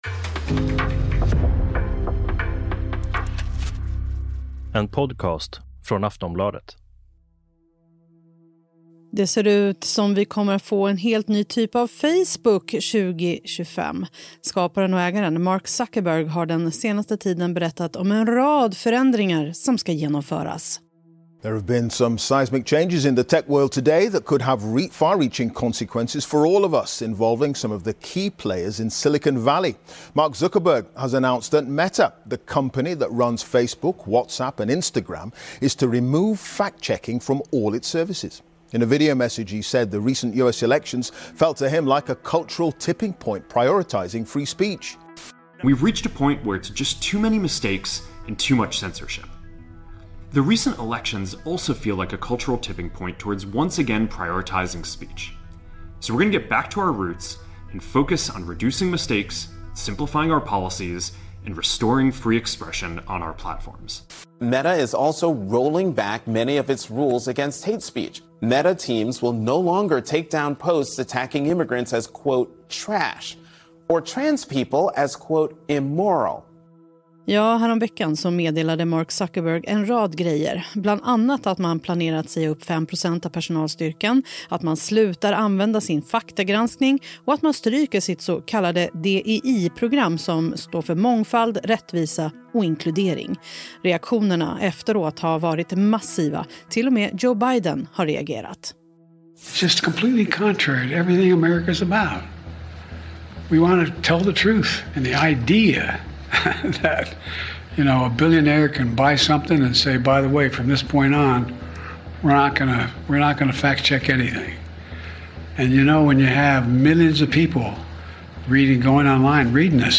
Klipp från: BBC, MSNBC, The Hill.